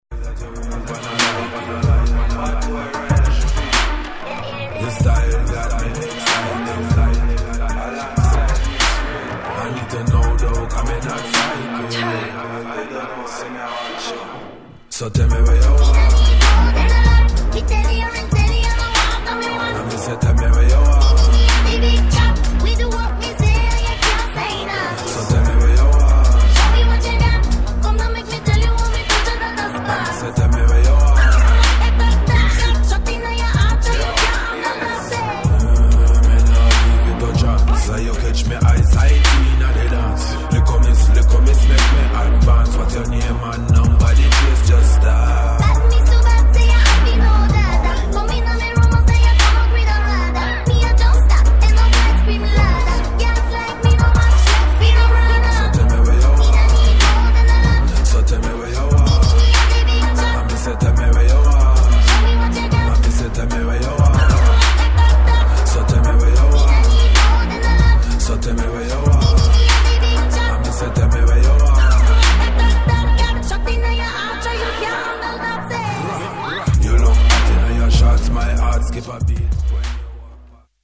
[ BASS / DUB / REGGAE ]